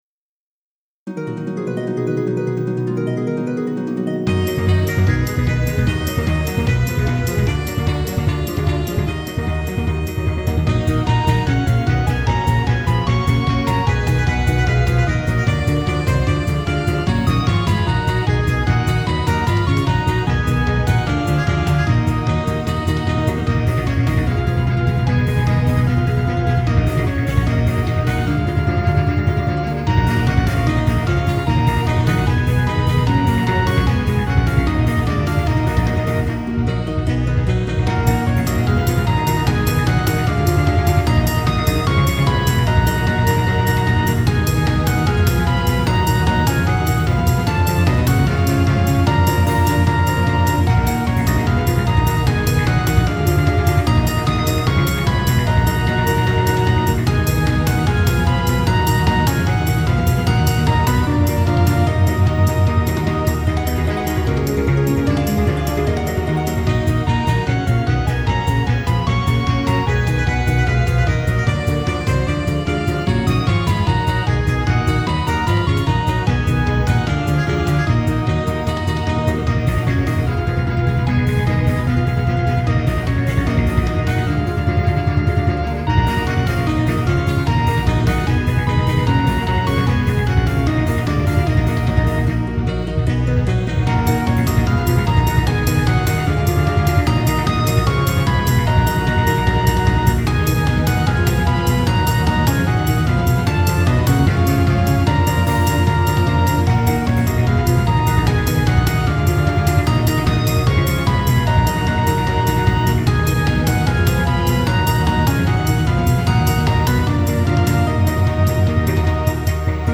変ホ短調